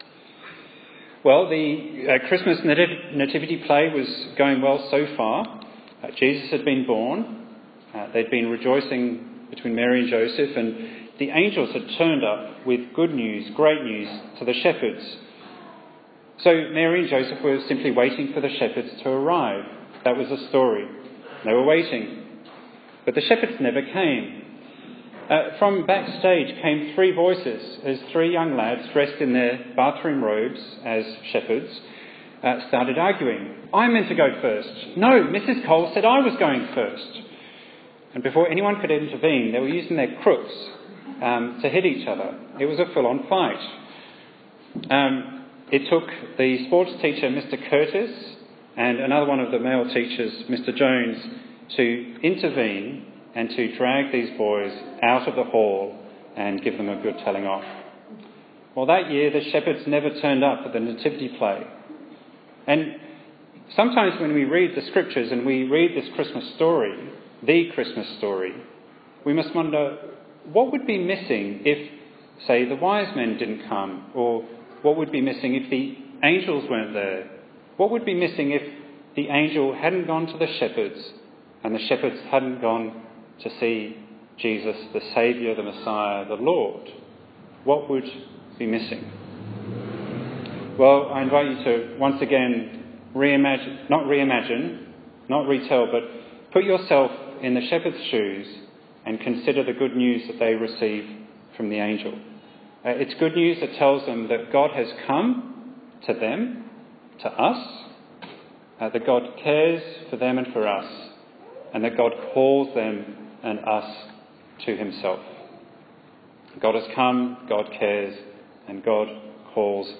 Bible Text: Luke 2:1-20 | Preacher